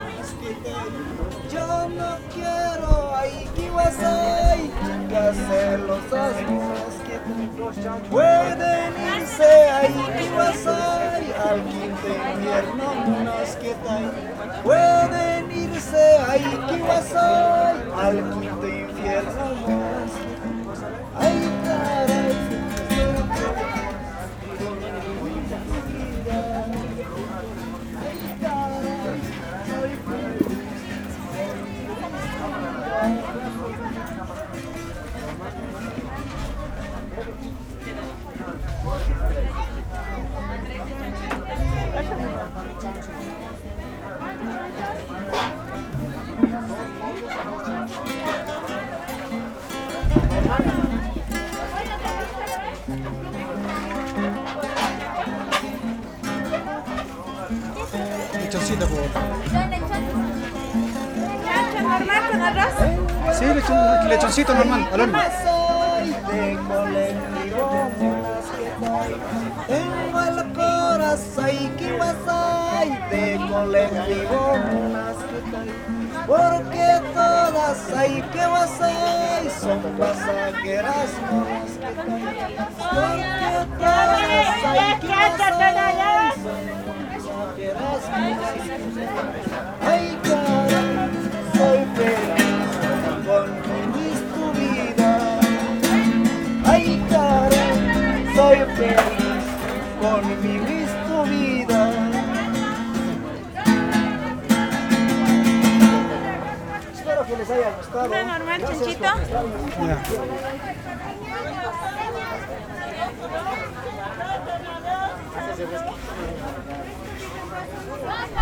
el-alto-cocineria.wav